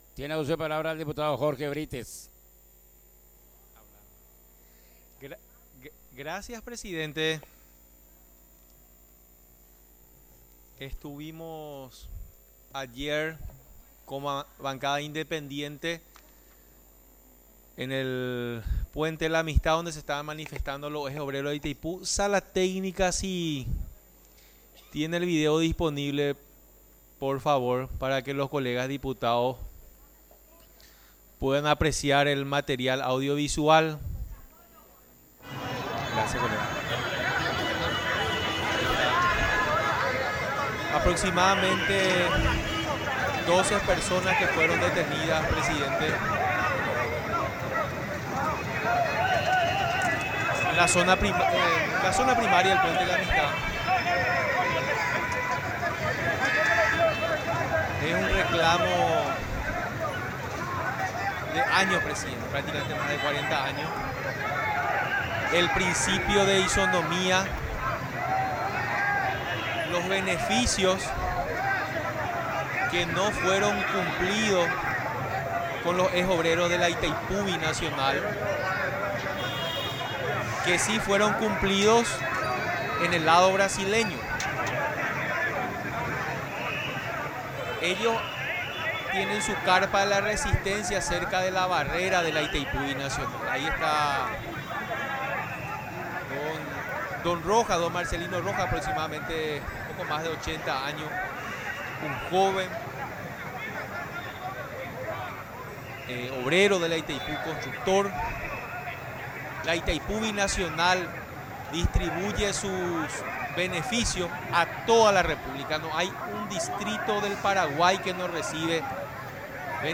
Sesión Extraordinaria, 19 de abril de 2023
Oradores. 1 (uno) por bancada